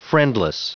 Prononciation du mot friendless en anglais (fichier audio)
Prononciation du mot : friendless